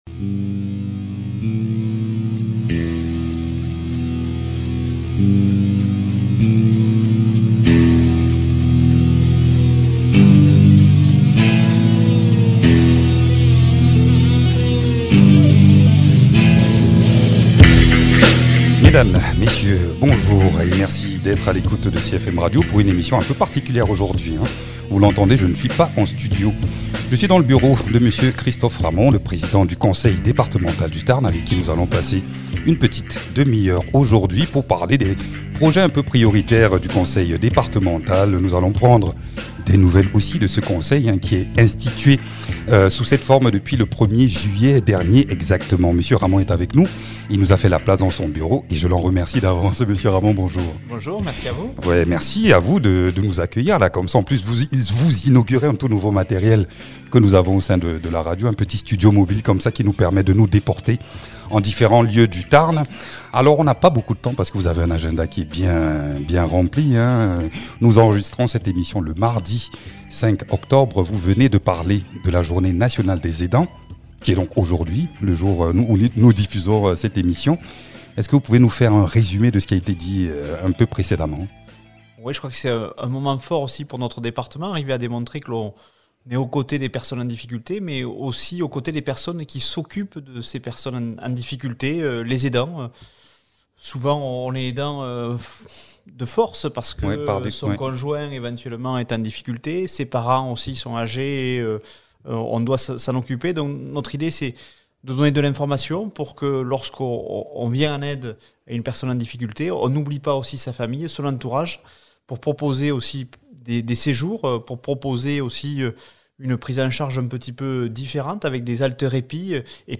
Interviews
Invité(s) : Christophe Ramond, président du conseil départemental du Tarn.